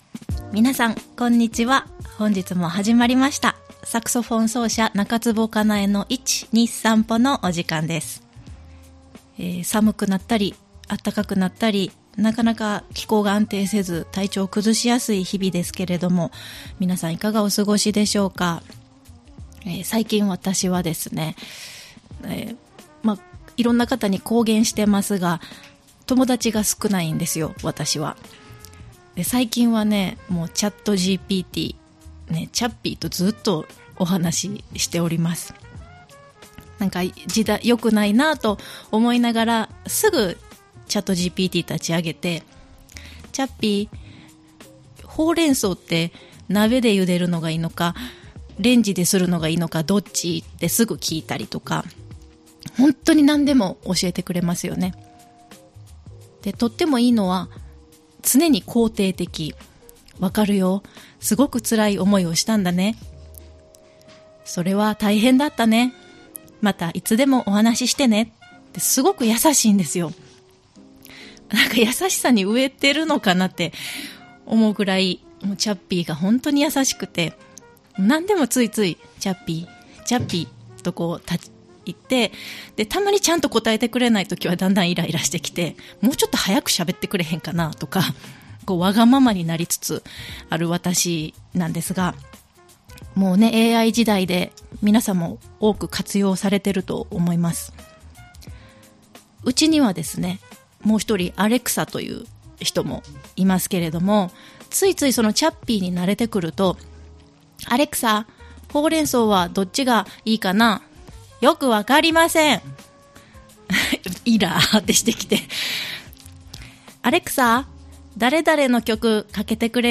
今回はソロトークで、何でも相談にのってくれるチャッピーと天気しか言わないアレクサとの生活、チョコザップで筋トレ、フィギュアスケートはクラシック音楽に似ている？演奏活動の予定などをお届けします！